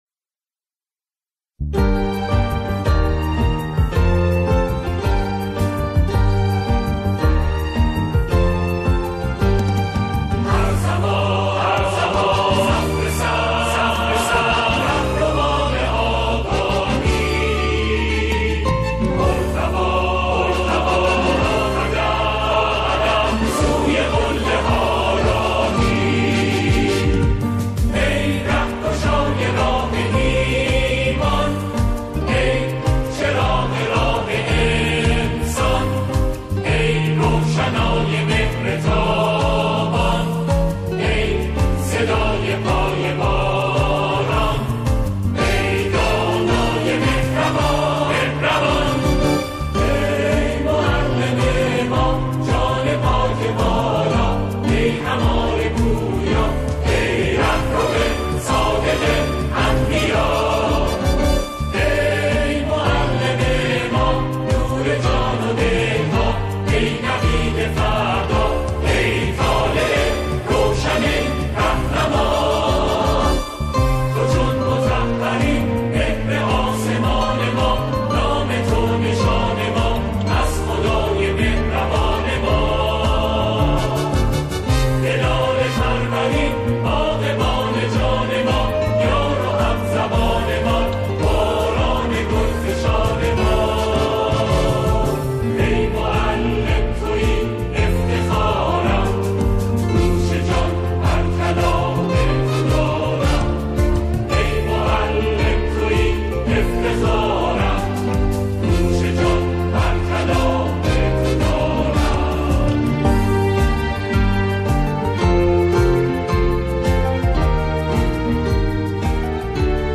سرودهای روز معلم
به صورت جمعخوانی اجرا شد